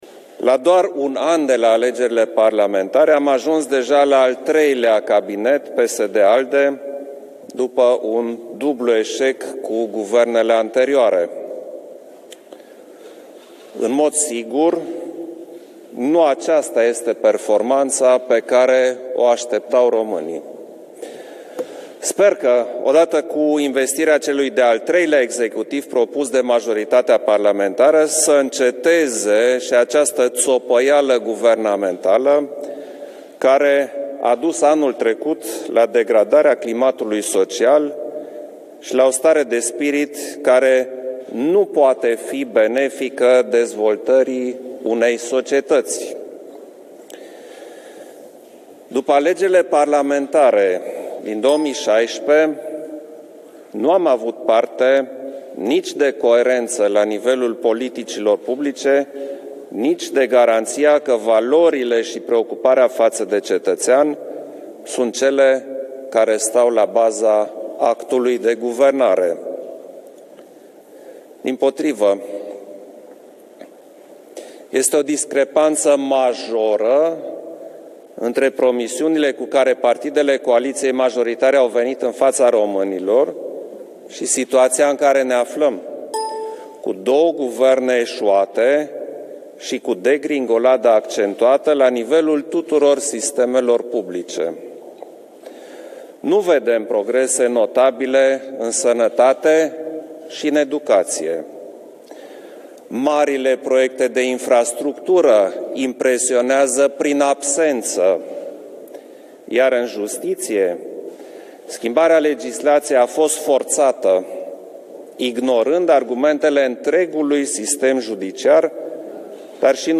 La ceremonia depunerii jurământului de către echipa condusă de premierul Viorica Dăncilă, şeful statului şi-a exprimat speranţa că, odată cu al treilea executiv propus de coaliţia PSD-ALDE, va înceta “această ţopăială guvernamentală”.
Ceremonia completă de la Palatul Cotroceni